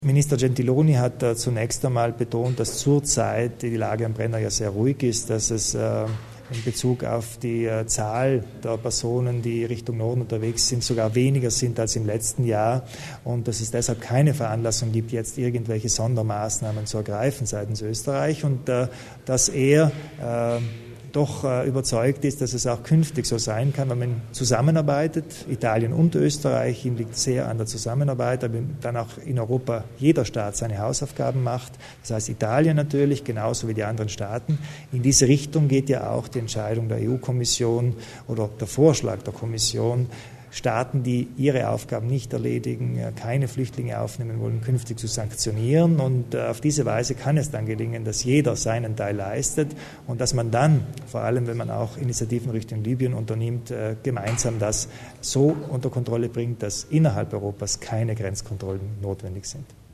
Landeshauptmann Kompatscher über das Treffen mit Minister Gentiloni